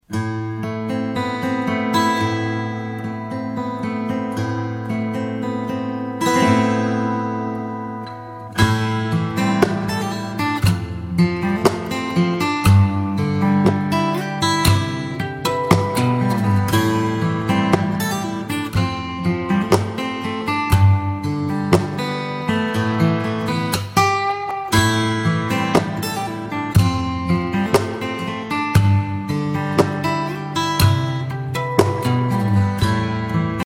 Мелодия на будильник - Гитара